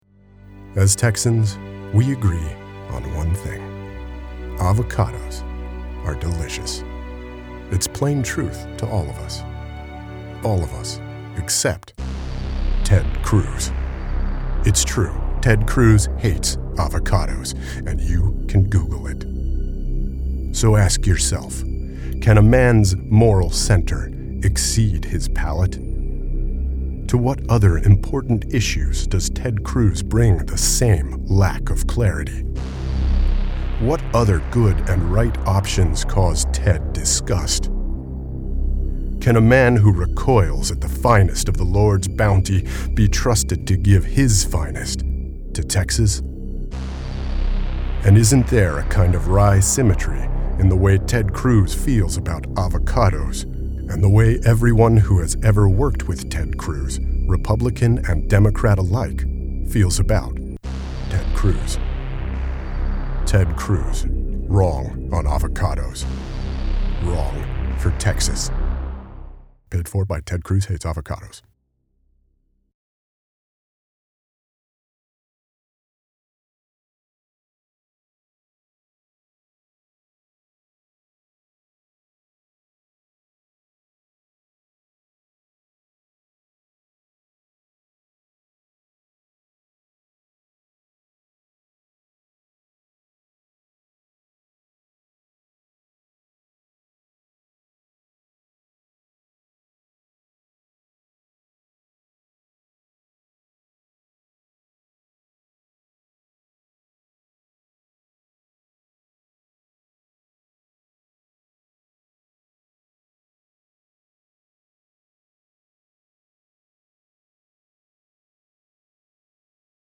Here’s a quick and dirty video version of the fake attack ad from episode 1 to share, modify, steal, whatever.